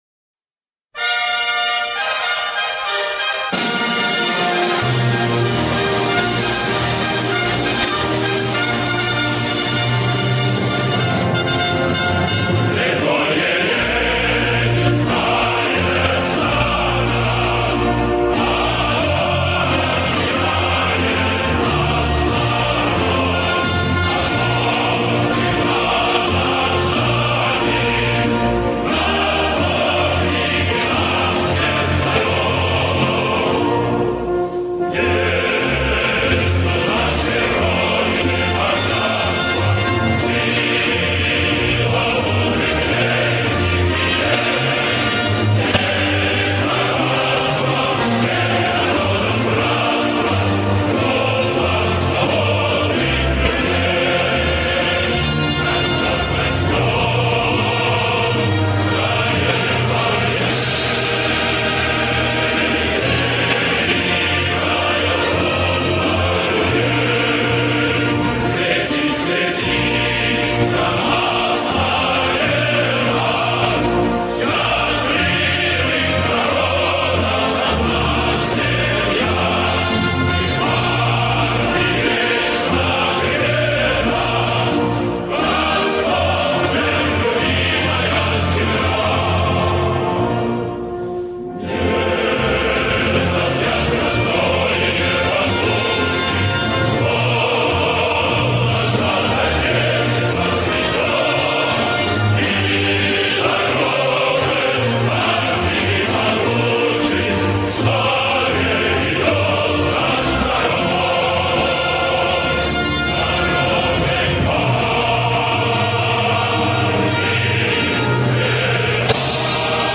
Soviet song about Lenin.